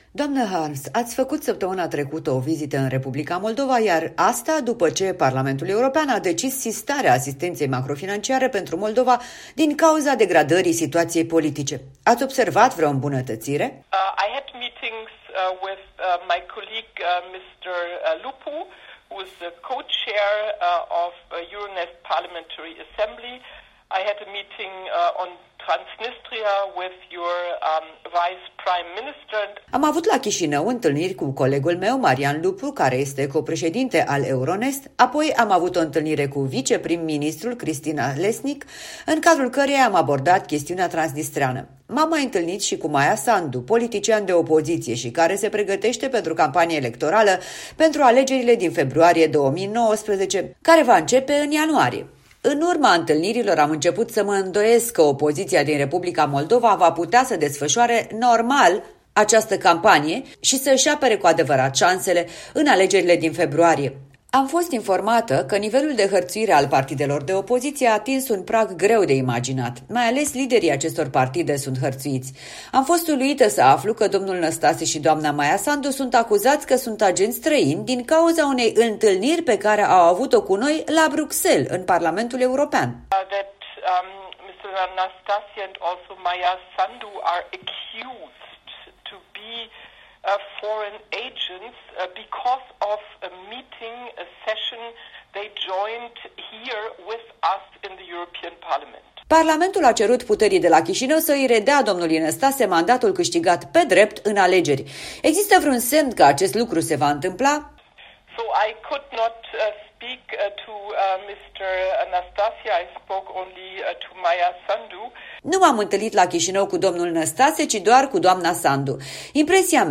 Interviu cu europarlamentara Rebecca Harms